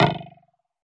Song: SFX dw hollow01mod